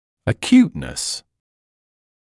[ə’kjuːtnəs][э’кйуːтнэс]острота (зрения, состояния, заболевания и пр.)
acuteness.mp3